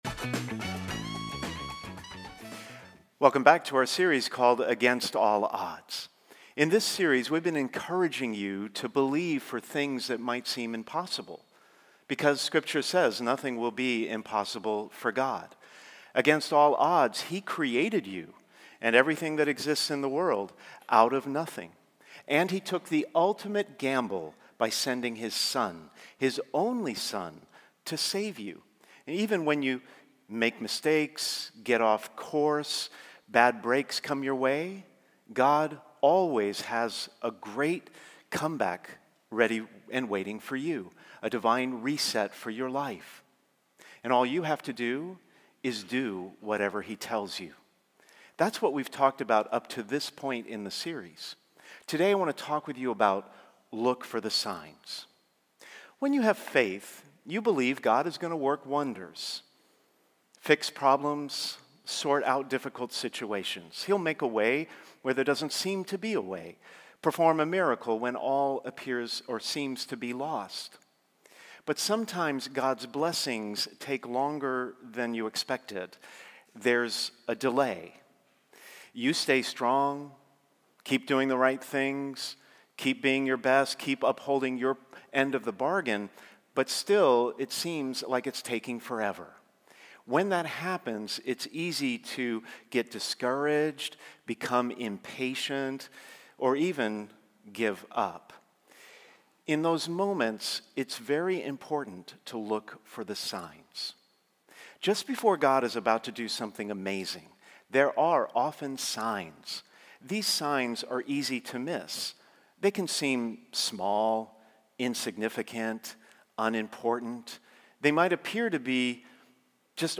Sermons | St. Hilary Church